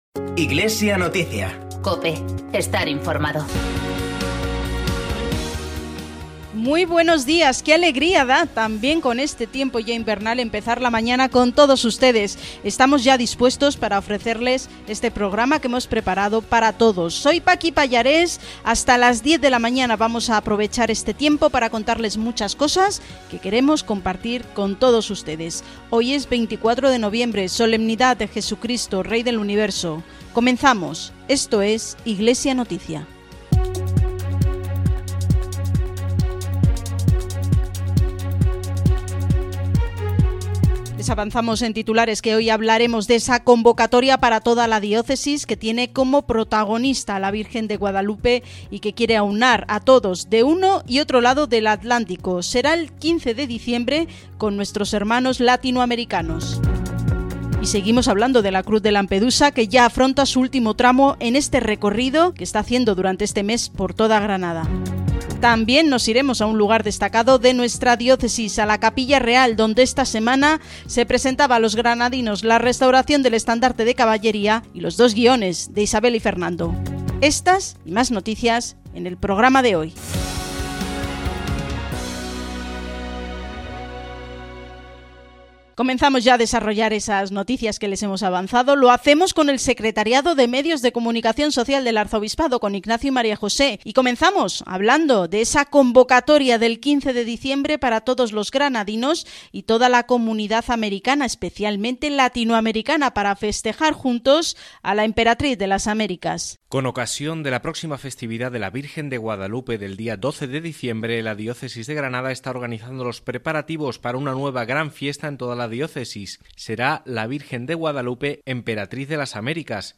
Podcast del informativo diocesano emitido el domingo 24 de noviembre en COPE Granada.